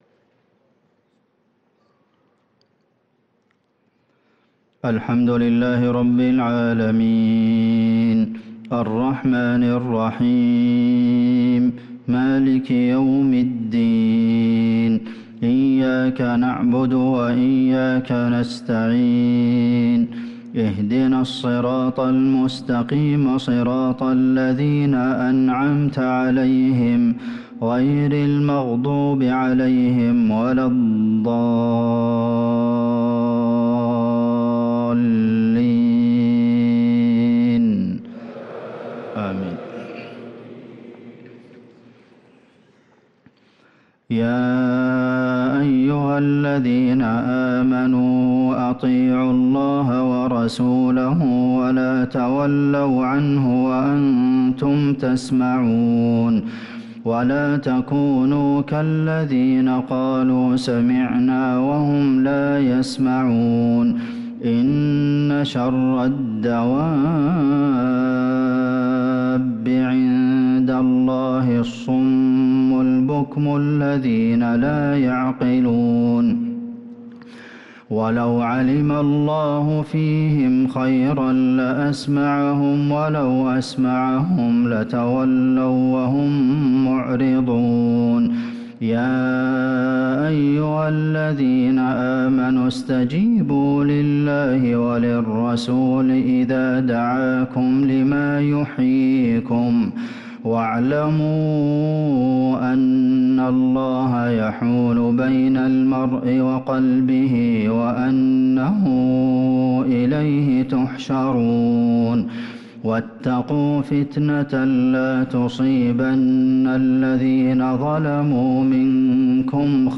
صلاة العشاء للقارئ عبدالمحسن القاسم 17 ربيع الآخر 1445 هـ